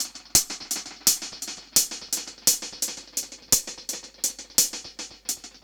Index of /musicradar/dub-drums-samples/85bpm
Db_DrumsB_EchoHats_85-02.wav